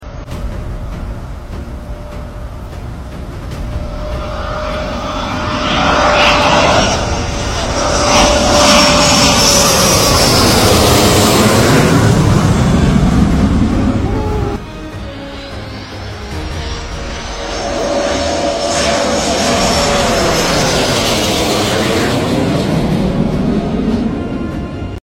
Jeneral Datuk Seri Norazlan Giro Mengetuai Perbarisan No.12 Skuadron Sukhoi Su 30MKM Flypast Merdeka 68